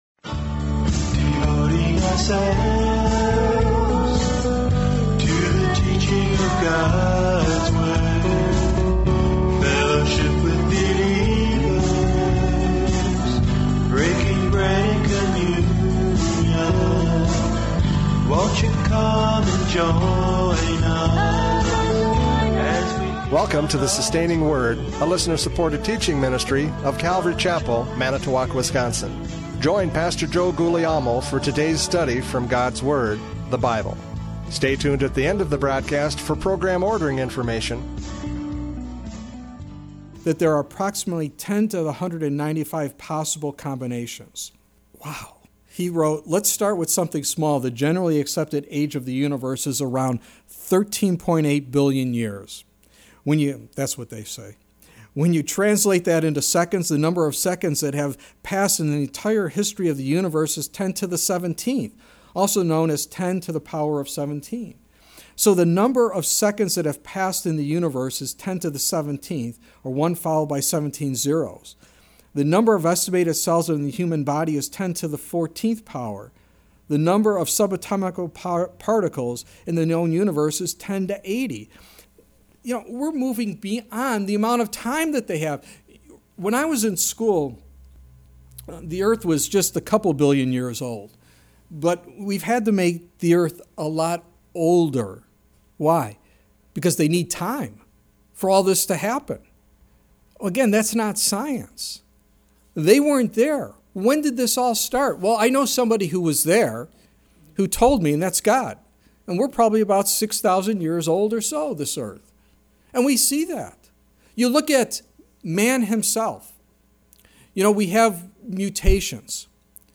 John 5:17-18 Service Type: Radio Programs « John 5:17-18 Equality in Creation!